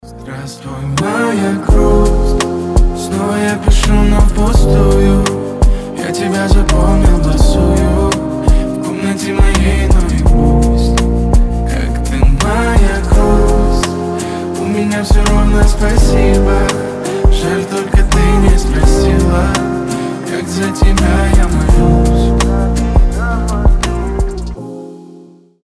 • Качество: 320, Stereo
лирика
грустные
красивый мужской голос
спокойные